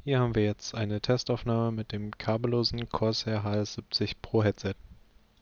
Mikrofonqualität
Man klingt mit dem Headset dumpf und der Kommentar „Sprichst du durch eine Dose“ ist gefallen. Entweder ist das Mikrofon einfach nicht gut oder eine integrierte Rauschunterdrückung tut einfach zu viel des Guten.
Testaufnahme Corsair HS70 Pro
Corsair_HS70_Pro_Testaufnahme.wav